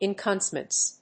音節en・cum・brance 発音記号・読み方
/ɪnkˈʌmbrəns(米国英語)/